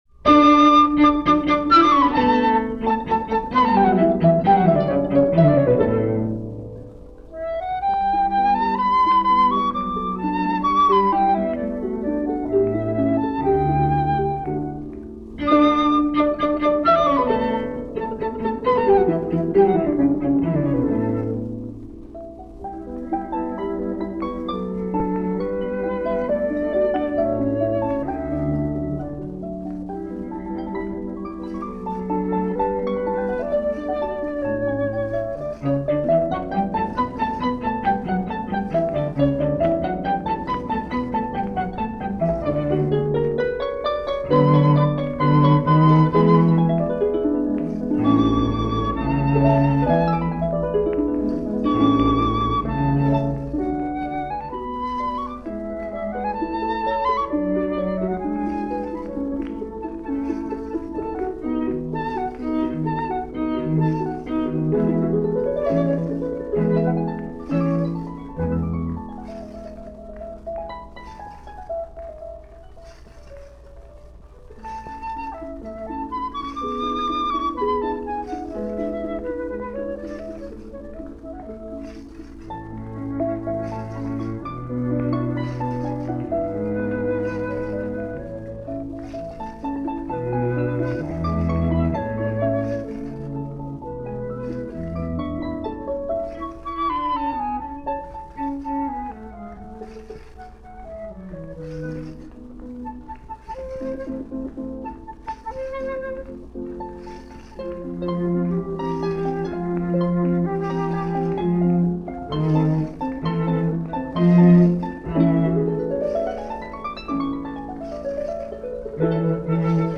from a radio session for the ORTF in Paris, recorded late 1950/early 1951.
Featured is the Trio op. 5 for Harp, Flute and Cello.
Boieldieu-Trio-Opus-5-Harp-Flute-Cello-1951.mp3